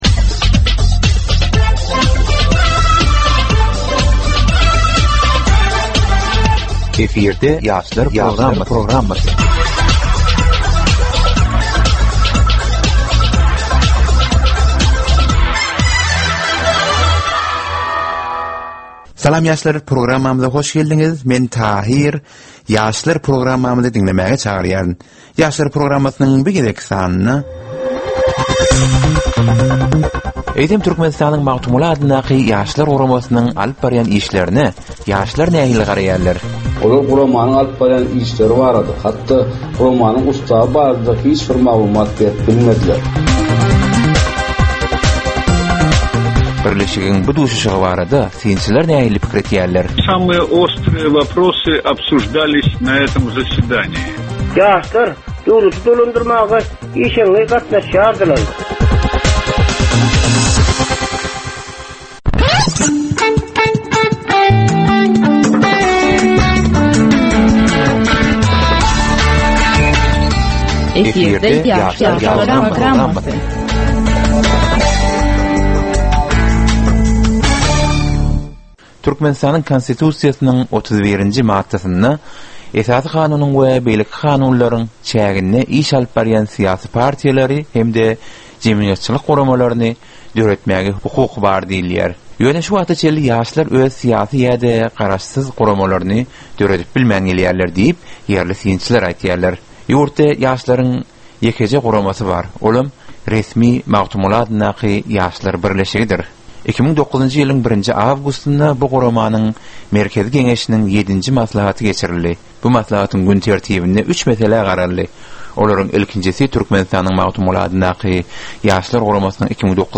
Geplesigin dowmynda aýdym-sazlar hem esitdirilýär.